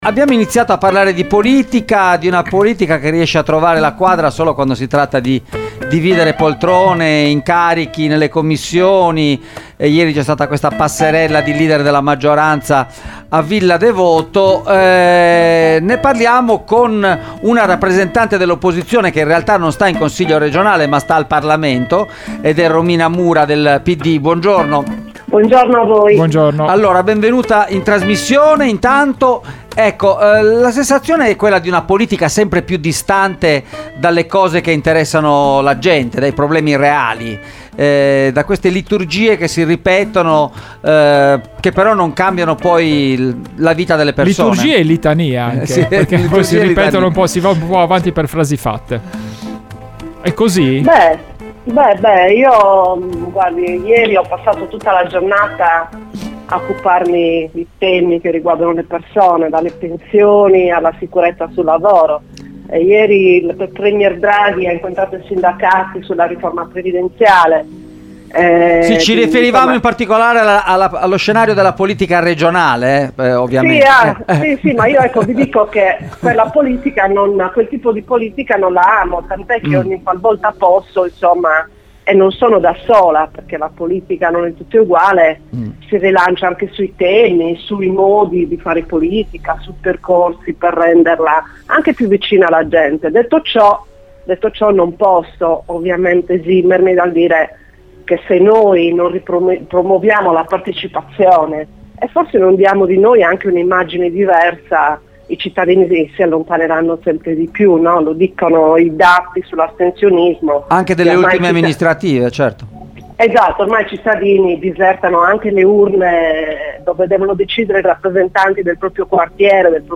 Romina Mura, parlamentare del PD e possibile candidata unitaria alla segreteria del PD sardo è intervenuta ai microfoni di Extralive per ragionare sul rapporto tra la politica e i cittadini e per fare il punto della situazione sull’imminente congresso del PD: